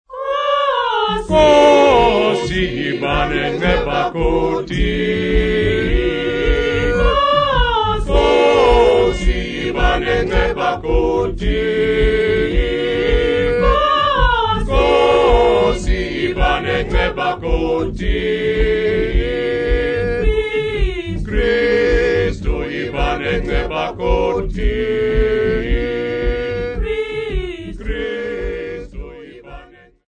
Choir
Folk music--Africa
Field recordings
Africa South Africa Mount Ayliff f-sa
sound recording-musical
Indigenous music, Choral music.
3.75ips reel